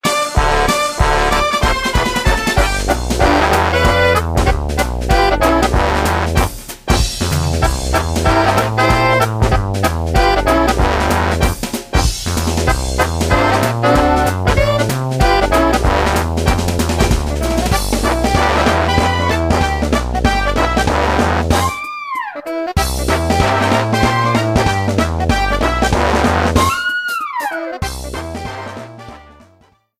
Background music
Reduced length to 30 seconds, with fadeout.